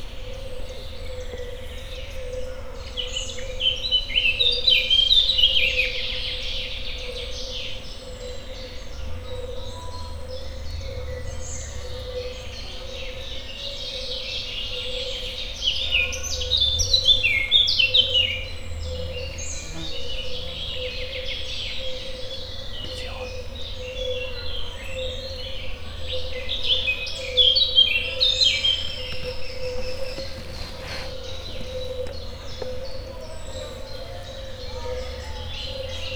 koncert_madar_tiszababolna00.36.wav